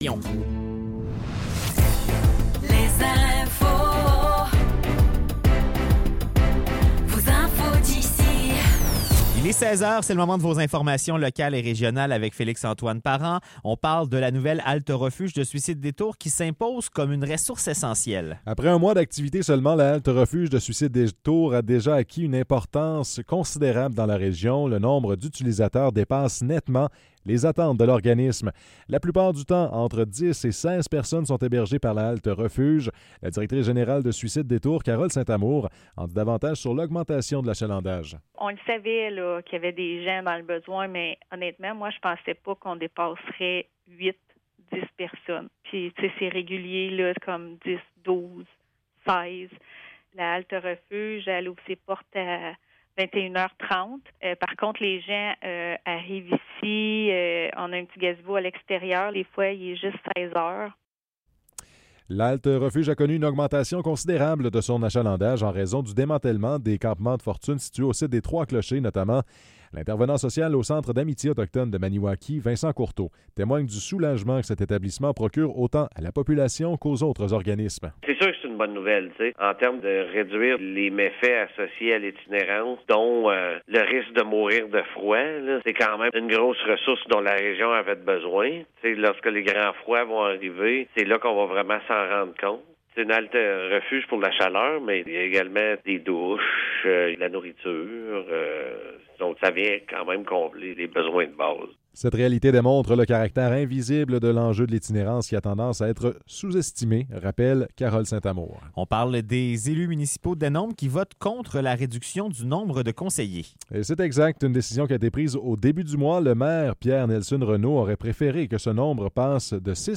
Nouvelles locales - 28 novembre 2024 - 16 h